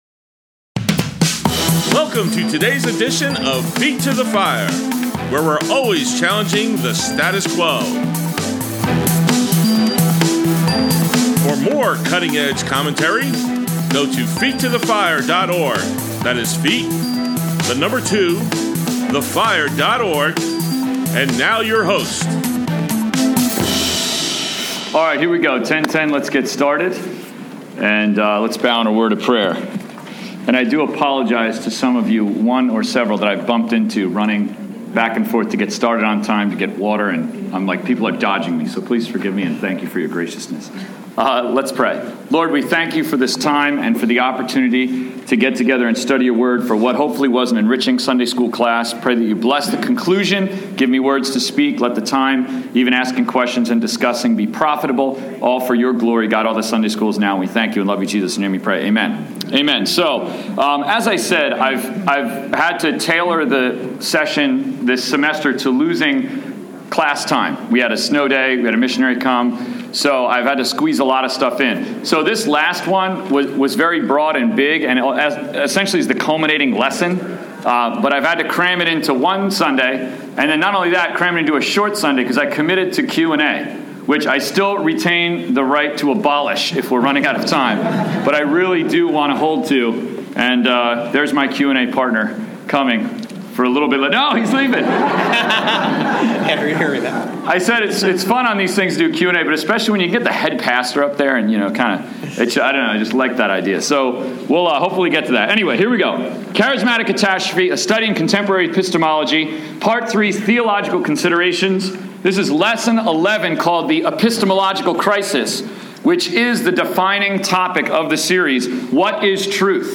Grace Bible Church, Adult Sunday School, 3/29/15, Final Session